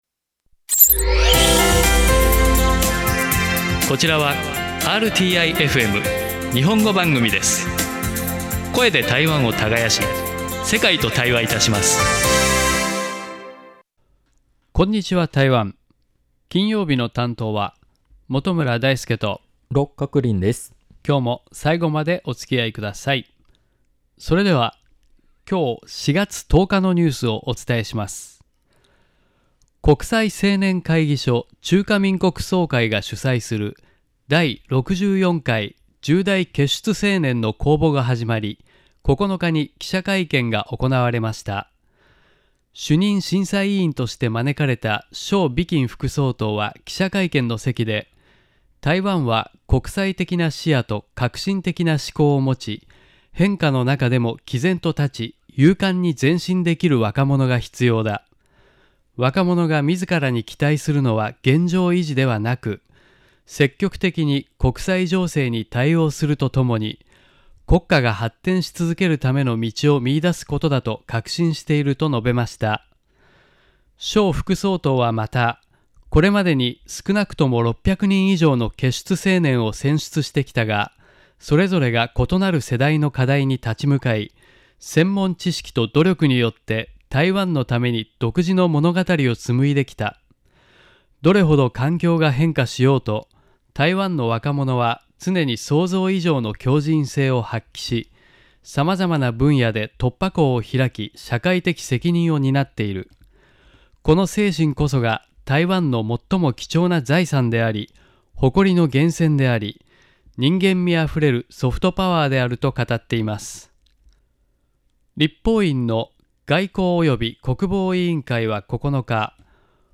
回想起上個月初次踏進錄音室，心情真的是既緊張又興奮 這段充滿文化火花的訪談終於正式上線囉！究竟我們在節目裡聊了哪些和菓子的精神哲學？